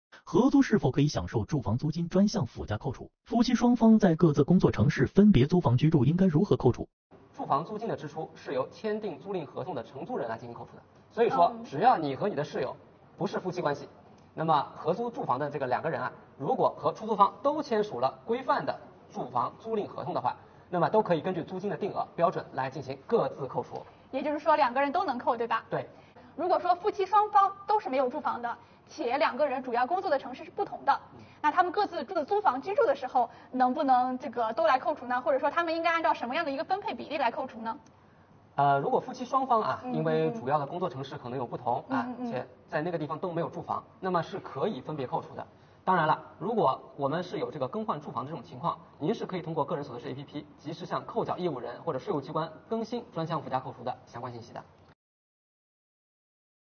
【涨知识】戳视频，看主播讲解关于住房租金专项附加扣除的那些事~